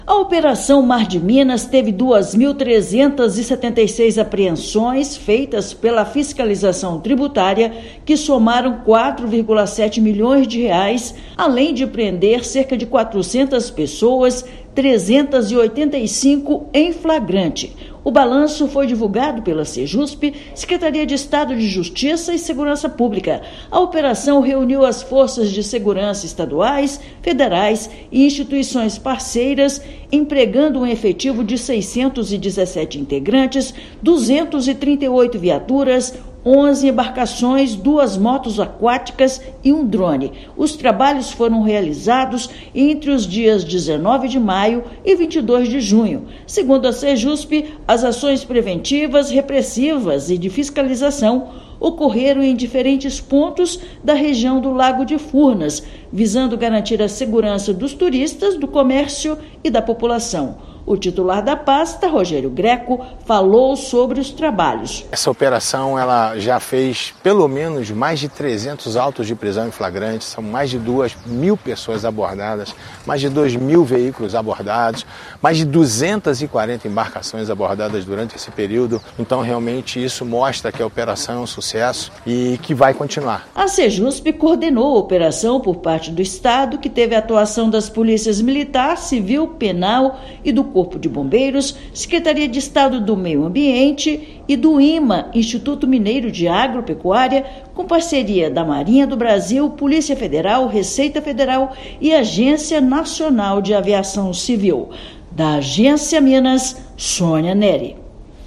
Forças de segurança do Estado atuaram de forma integrada, durante mais de um mês, combatendo irregularidades e crimes. Ouça matéria de rádio.